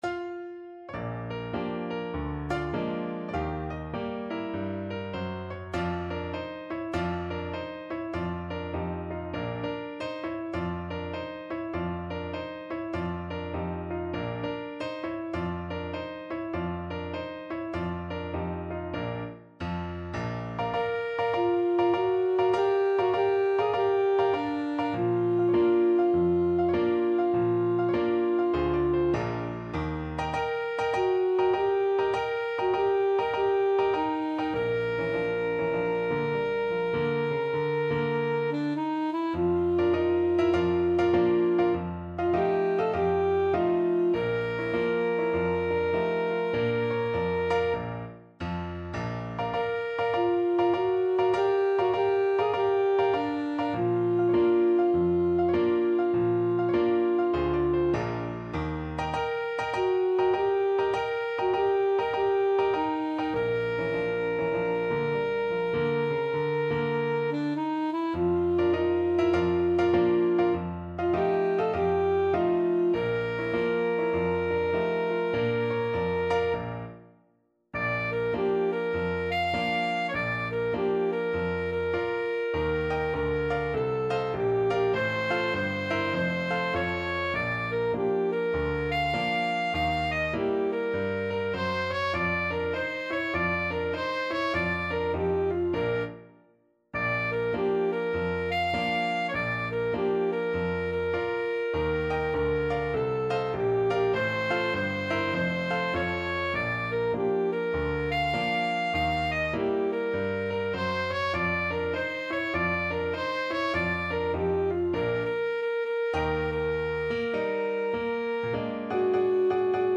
Alto Saxophone
4/4 (View more 4/4 Music)
Jazz (View more Jazz Saxophone Music)